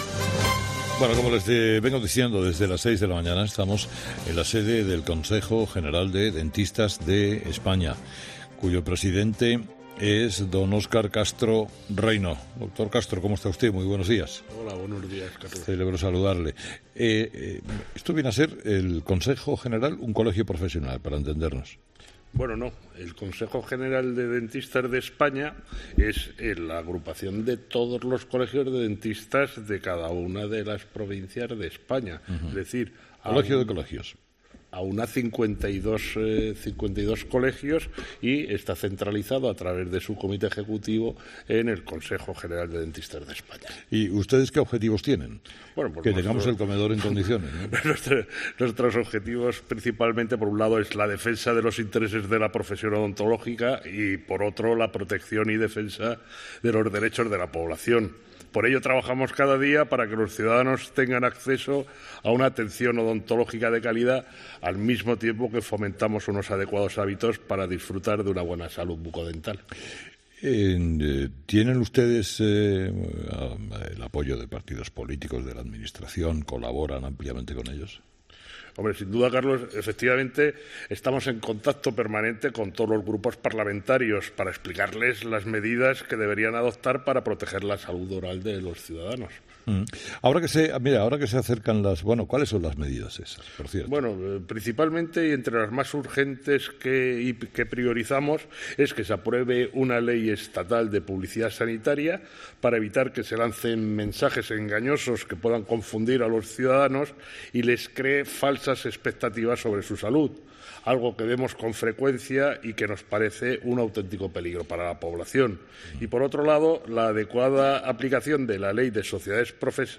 “Herrera en COPE” ha emitido este jueves, 17 de octubre, desde el Consejo General de Dentistas de España.